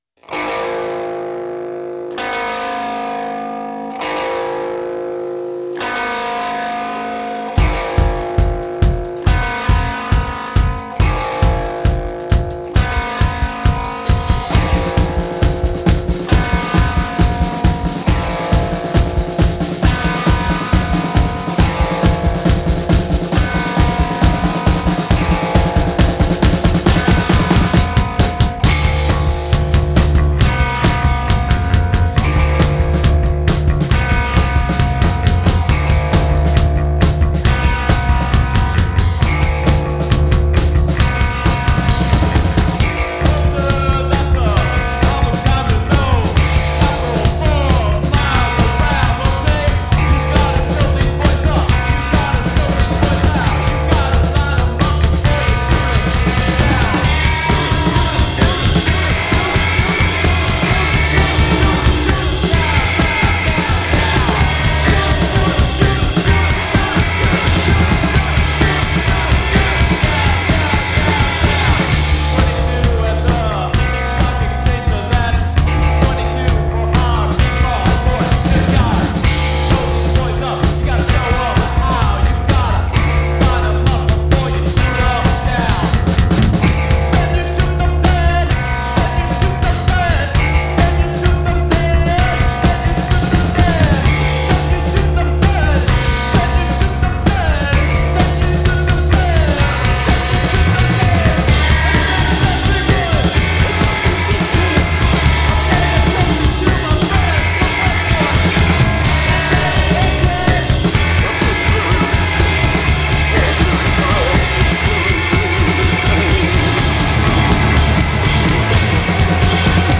Garage punk